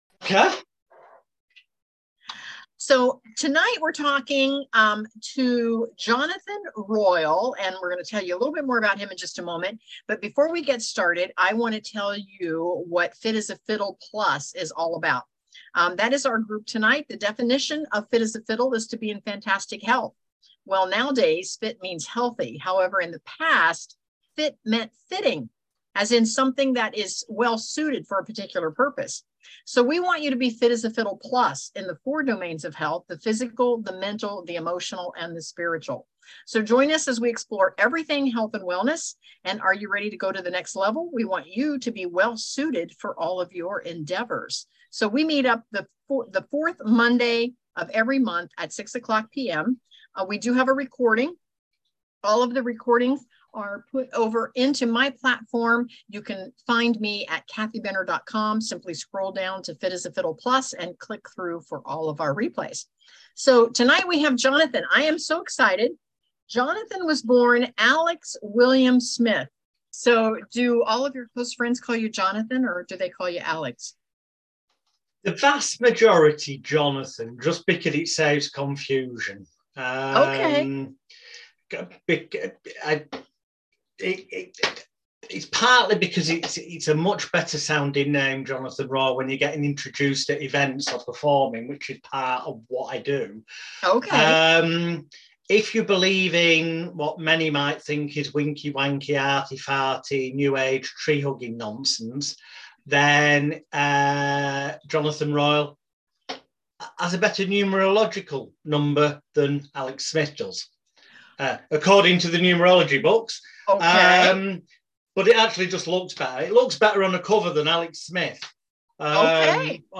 Fit As a Fiddle Plus | Interview